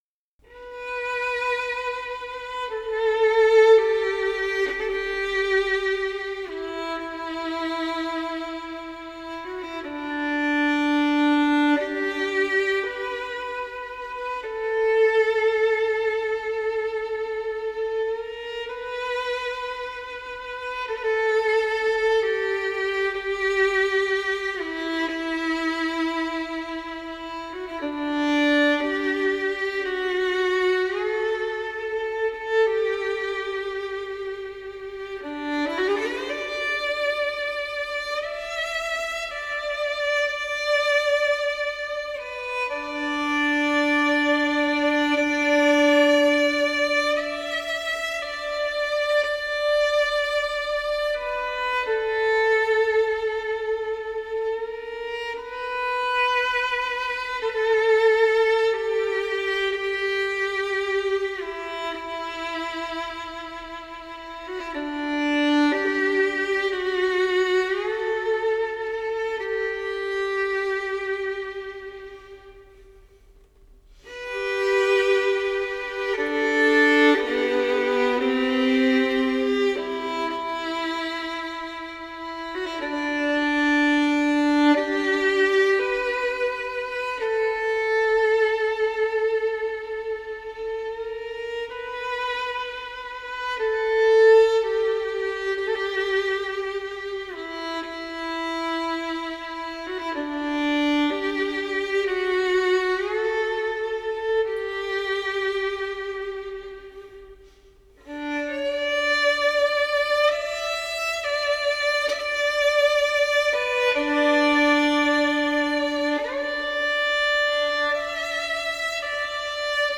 Выжившие пассажиры рассказывали, что слышали в ту ночь звуки популярного вальса "Songe d'Automne" и англиканского гимна "Nearer my God to thee".